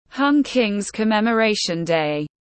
Ngày giỗ tổ Hùng Vương tiếng anh gọi là Hung Kings’ Commemoration Day, phiên âm tiếng anh đọc là /hʌŋ kɪŋz’ kəˌmeməˈreɪʃn deɪ/
Hung Kings’ Commemoration Day /hʌŋ kɪŋz’ kəˌmeməˈreɪʃn deɪ/
Hung-Kings-Commemoration-Day-.mp3